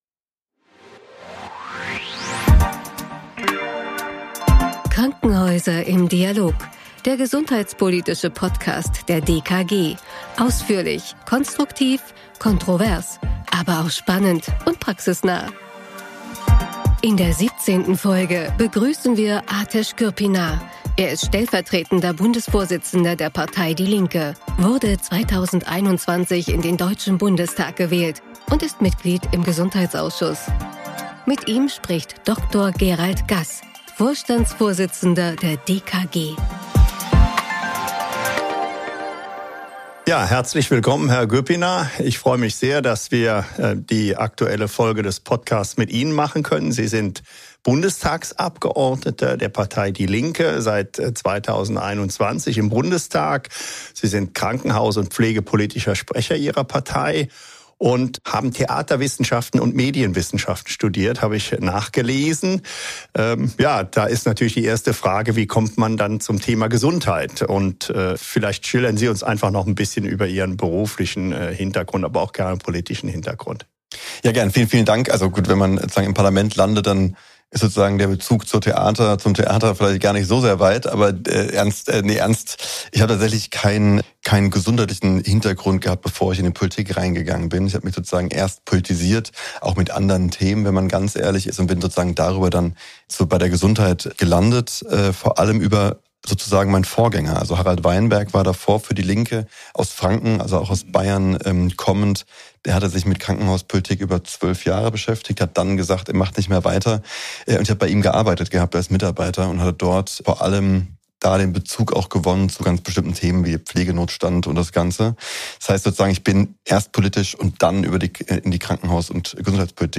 Krankenhäuser im Dialog - Folge 17 (mit Ateş Gürpınar) ~ Krankenhäuser im Dialog - der gesundheitspolitische Podcast der Deutschen Krankenhausgesellschaft Podcast